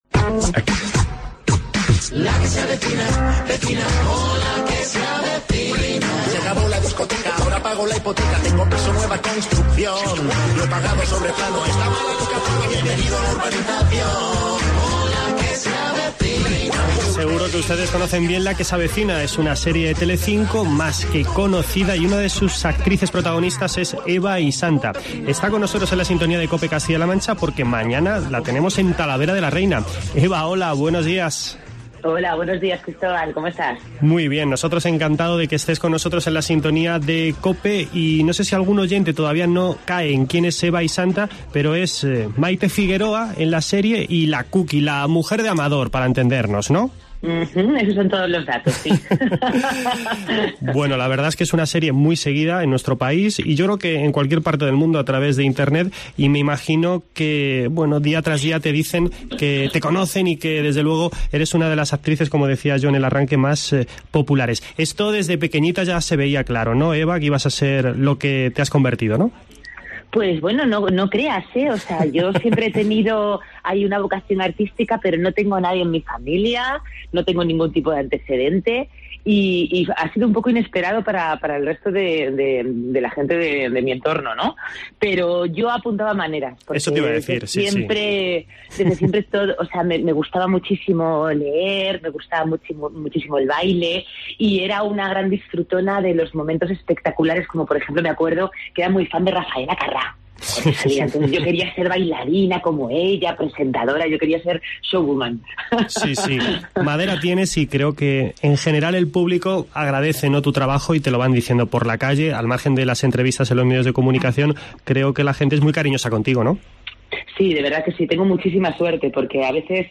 Eva Isanta, en la sintonía de COPE